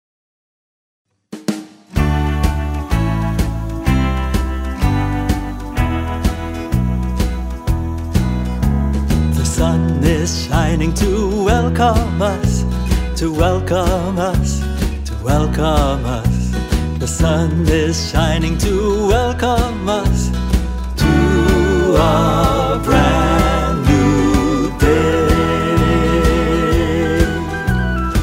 Educational Songs by Subject